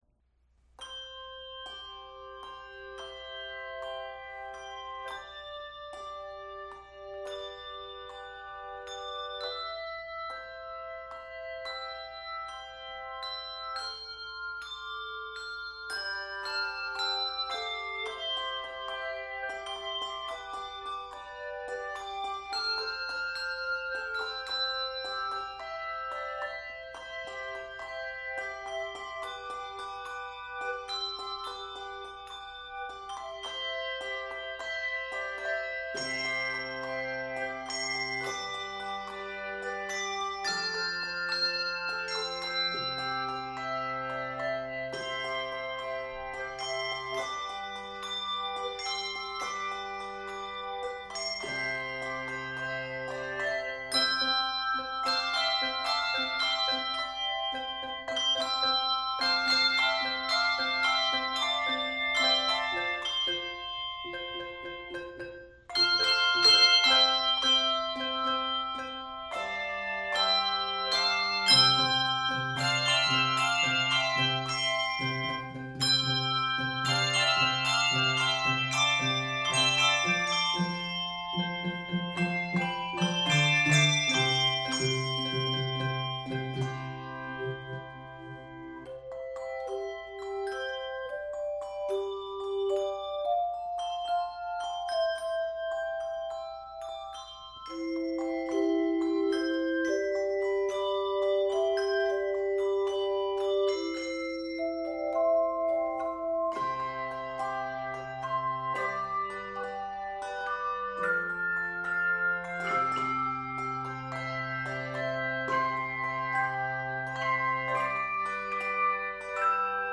This energetic setting of the contemporary worship song
Key of C Major.